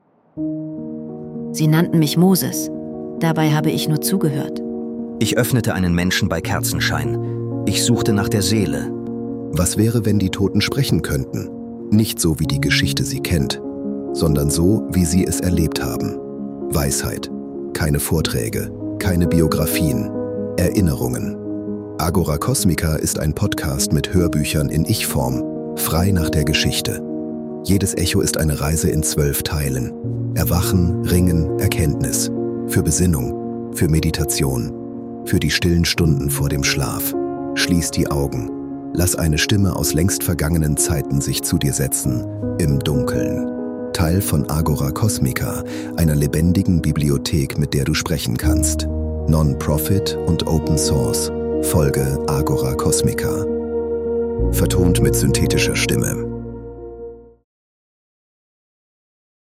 Trailer
Wir nutzen synthetische
Stimmen, damit diese Geschichten kostenlos bleiben, ohne Werbung —